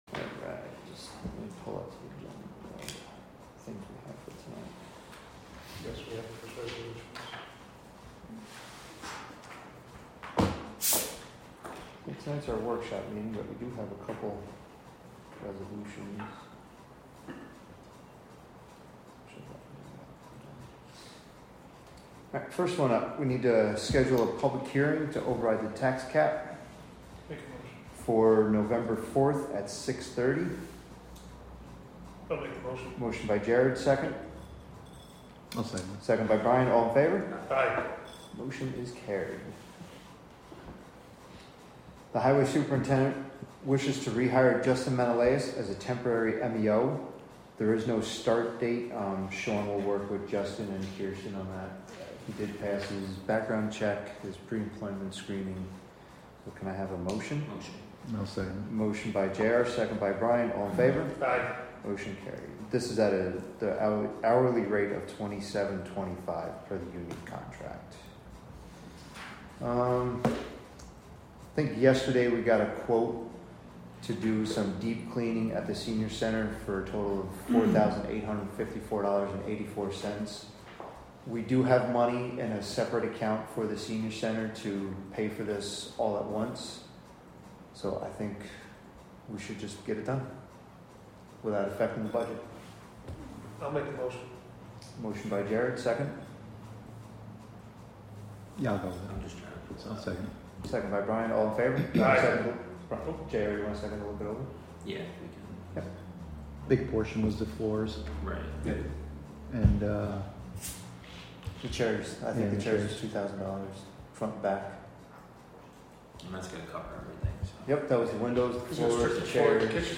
Live from the Town of Catskill: October 15, 2025 Catskill Town Board Meeting w/ Budget Workshop (Audio)